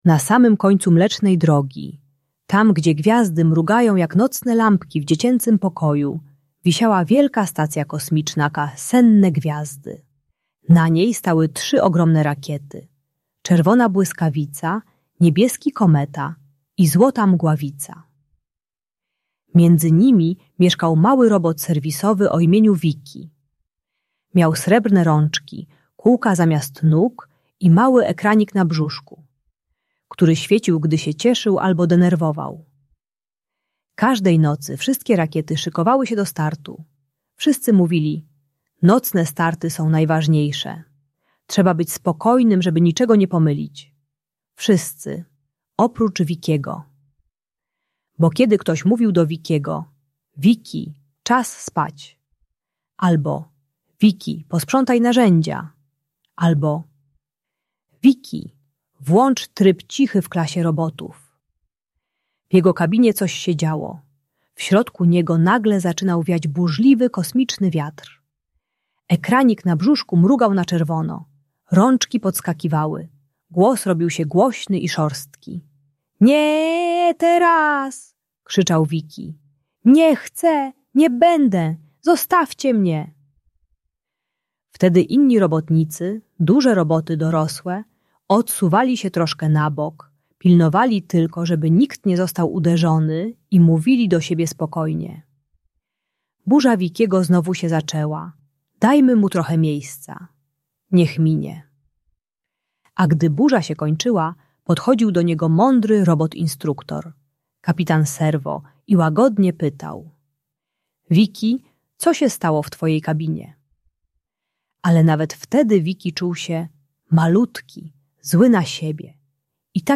Historia Vikiego i Rakiety Spokoju - Bunt i wybuchy złości | Audiobajka